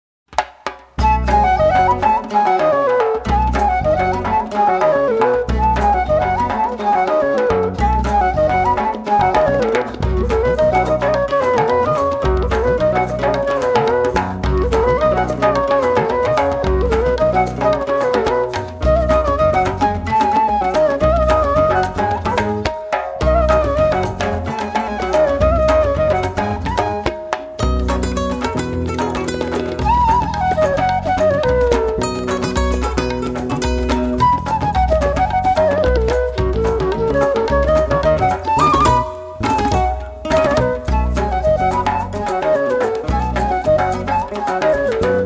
Genre: World Fusion.
bansuri
guitar
drums & percussion
tabla
double bass & electric bass
Recorded at Livingstone Studios, London